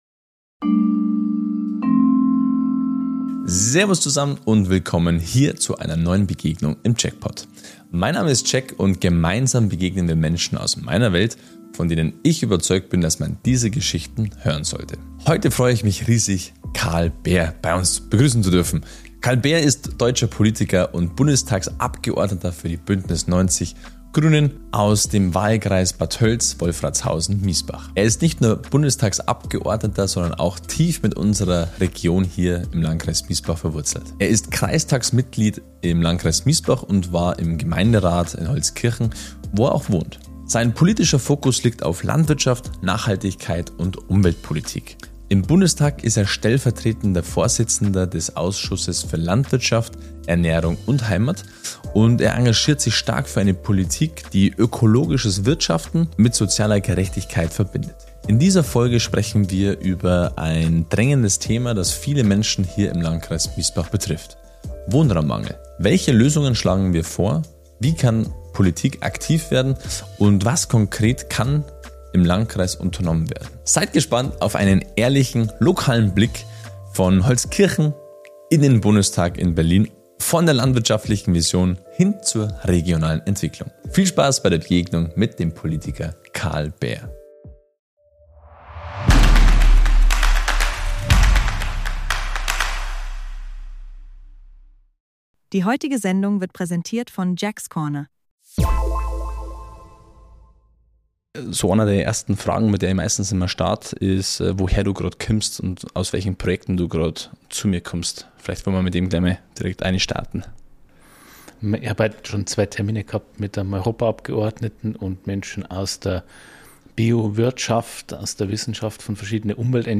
Wer kämpft heute für Demokratie? im Gespräch mit dem Bundestagsabgeordneten Karl Bär